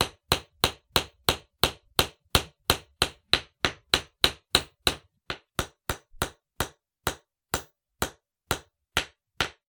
industrial_tools_hammer_hitting_nail